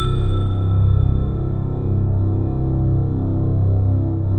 SI1 BELLS00L.wav